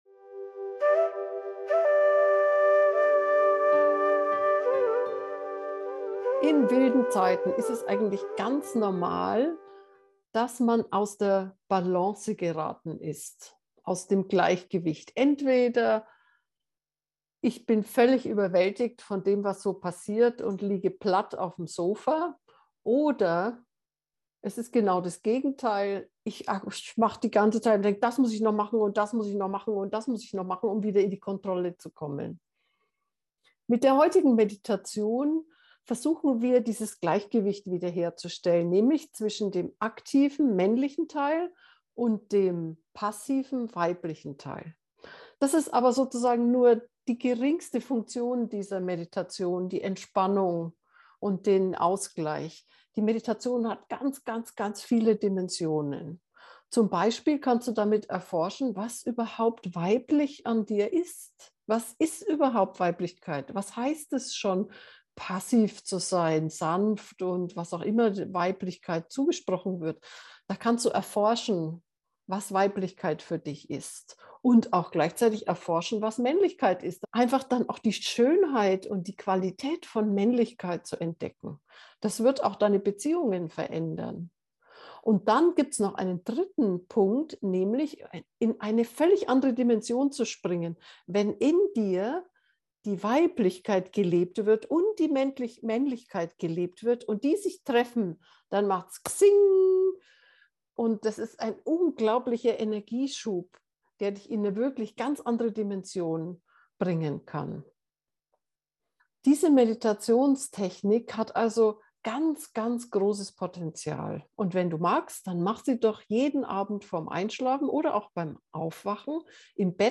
Atemmeditationen
Maennlichkeit-Weiblichkeit-einschlafen-meditation.mp3